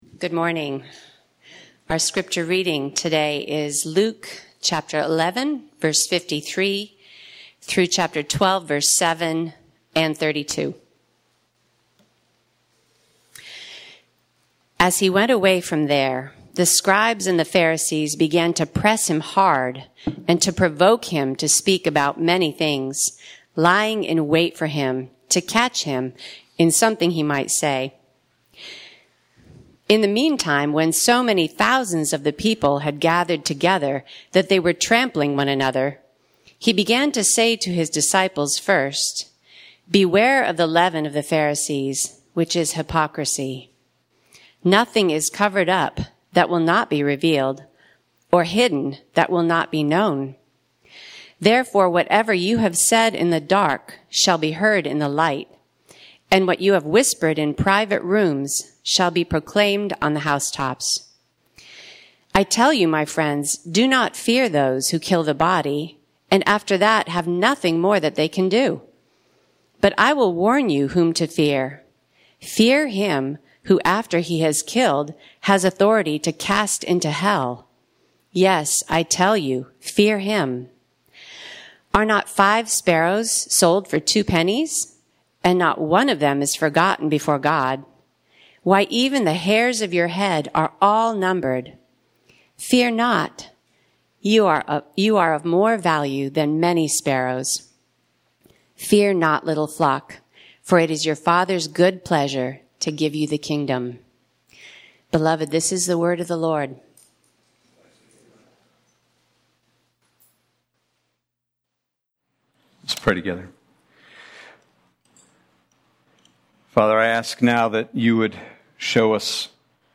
Format: Sermons